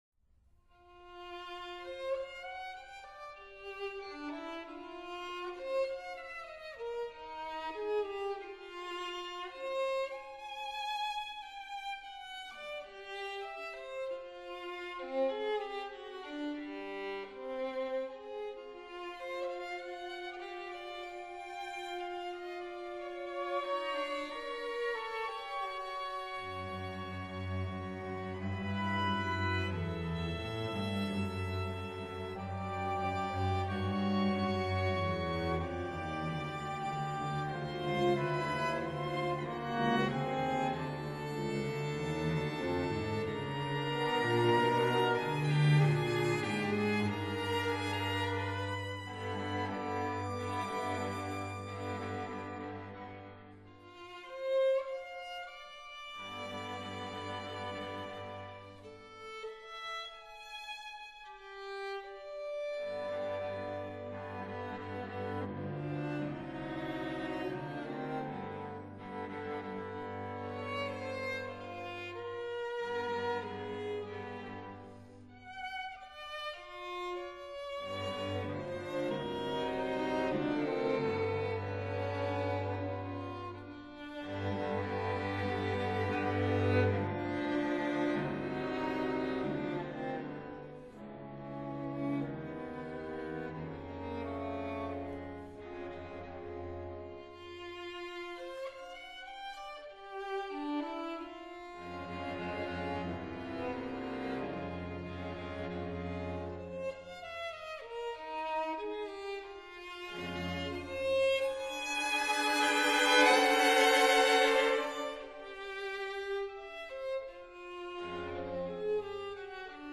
分辑：CD 23-27  弦乐四重奏全集